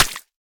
sounds / block / mud / break2.ogg
break2.ogg